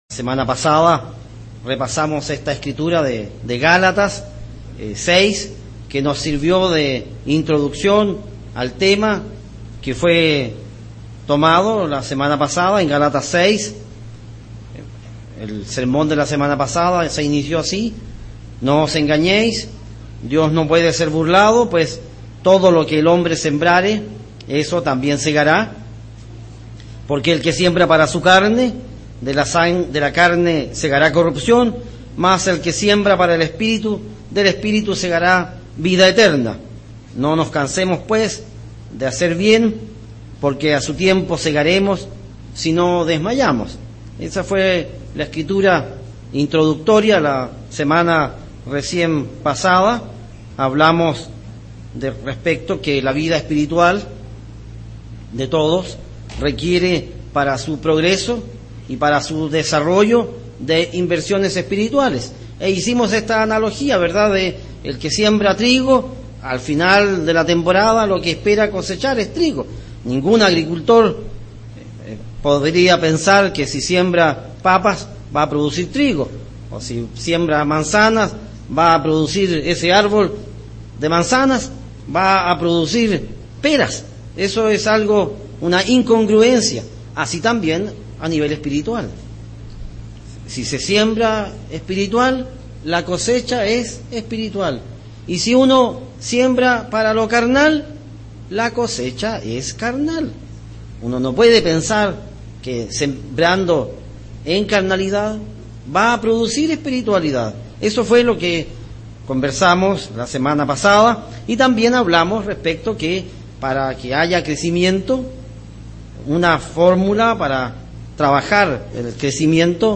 Facilitadores del desarrollo espiritual | Iglesia de Dios Unida